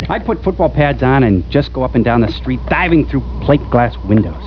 - MST3K Crow saying: "I'd put football pads on and just go up and down the street, diving through plateglass windows."